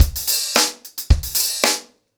DaveAndMe-110BPM.1.wav